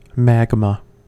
magma-us.mp3